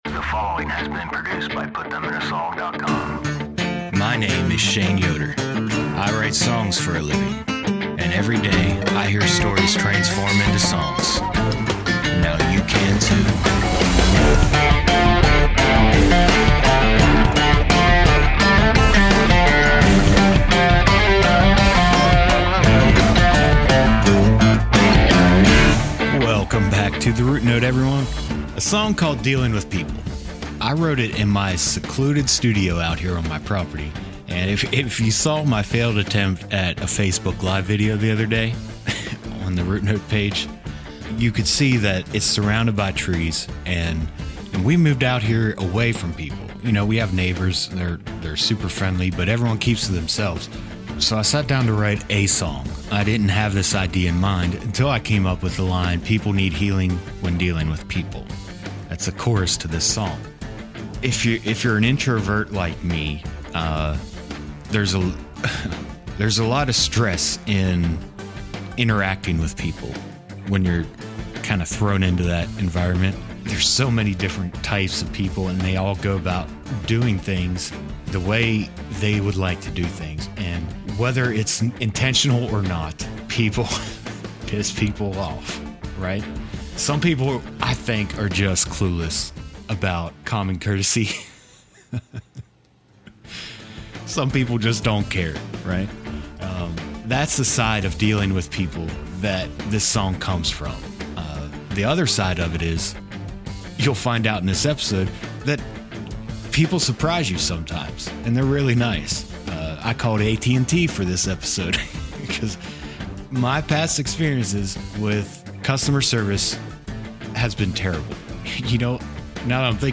It showcases how stories, feelings and loved ones transform into a song. The high quality production and family friendly content allows listeners to easily connect with the guests.